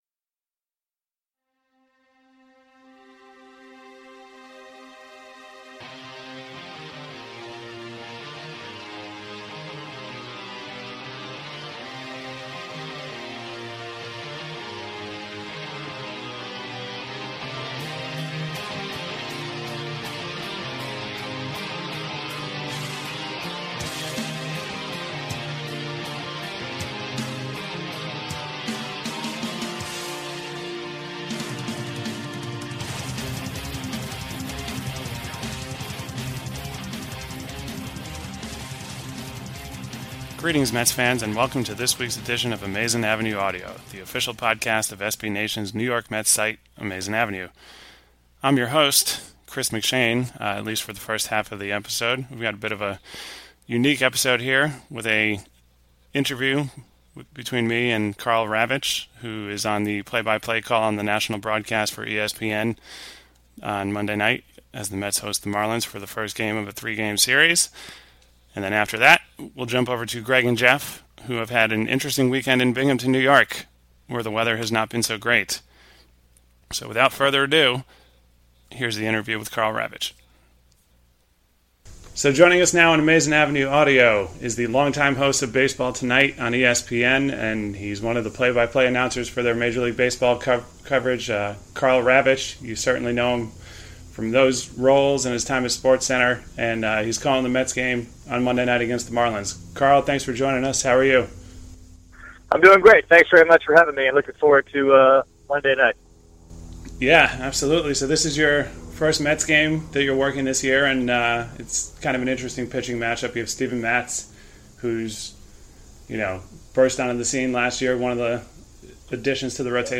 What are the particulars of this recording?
make compelling radio in a Comfort Inn in Binghamton, NY after a snowout.